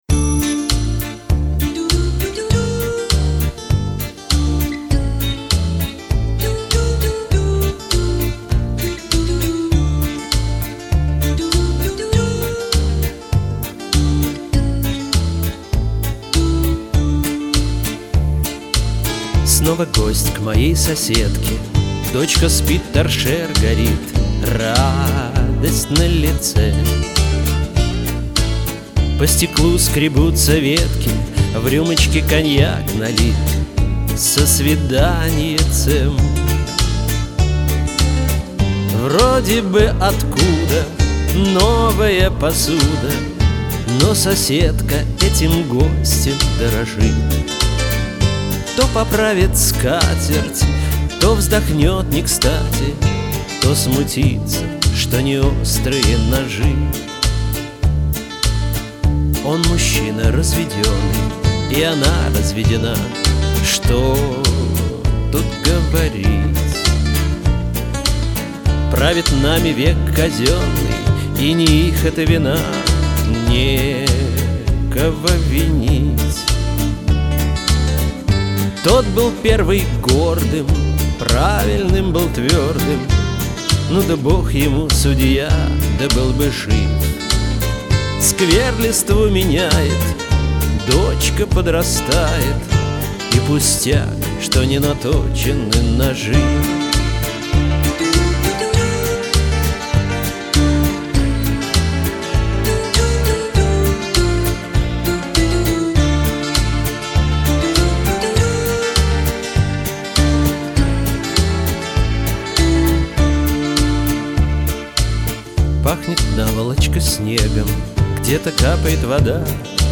нет слов!!! как всегда шикарное звучание, шикарный минус!